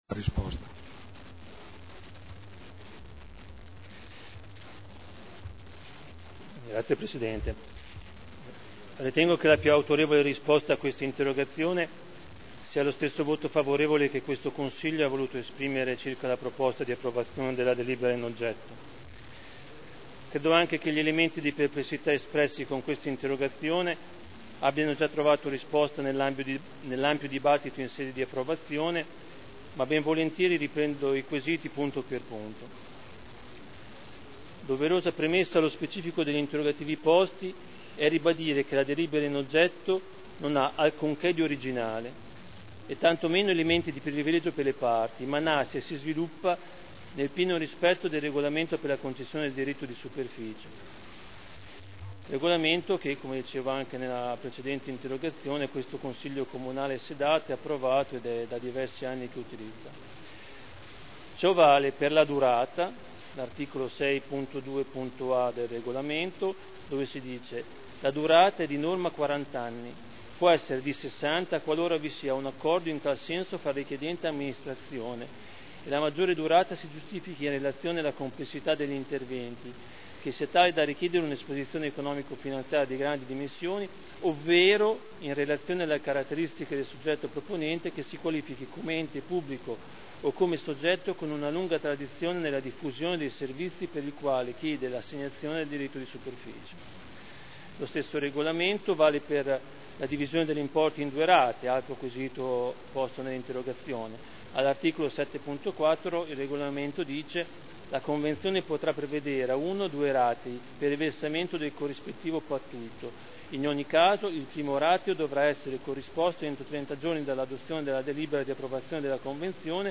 Fabio Poggi — Sito Audio Consiglio Comunale
Seduta del 24/11/2011. Risponde a interrogazione dei consiglieri Galli, Pellacani, Taddei (PdL) sulla Coop. “Aliante” – Primo firmatario consigliere Galli (presentata il 4 novembre 2010 - in trattazione il 24.1.2011)